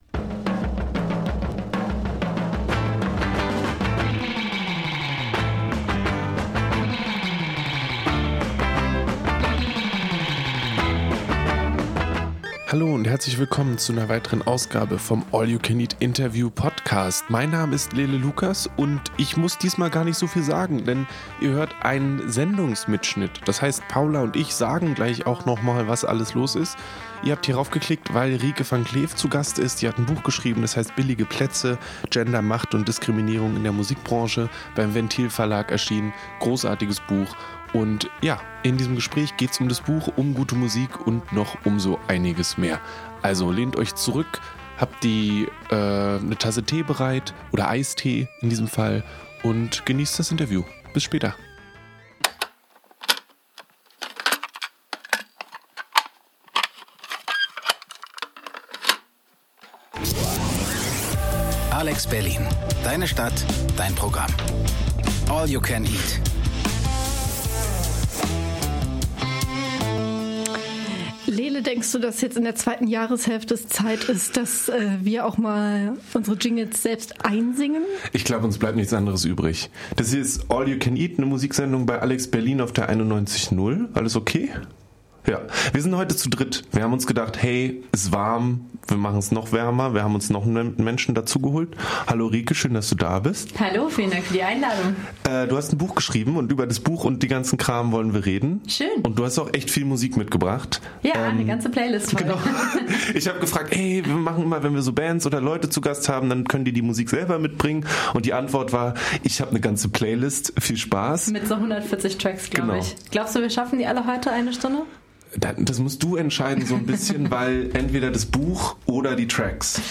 Der Brauch – Ein Interview mit The Hirsch Effekt